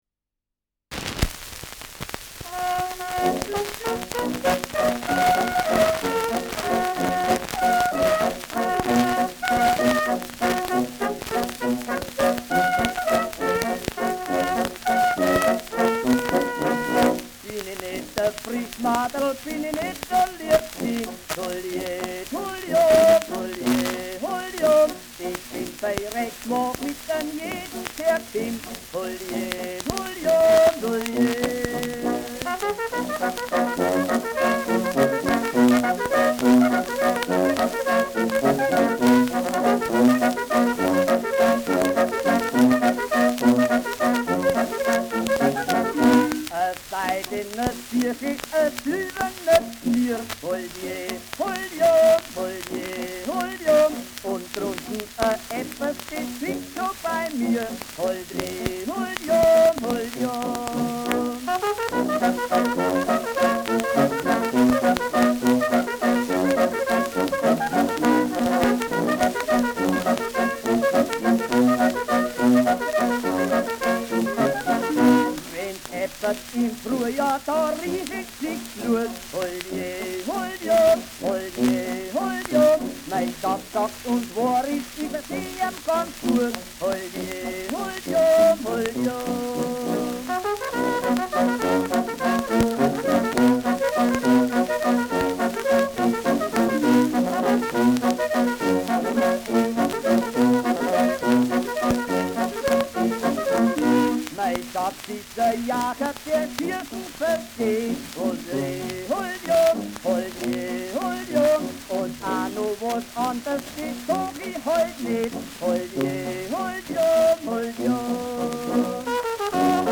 Schellackplatte
präsentes Rauschen : leichtes Knacken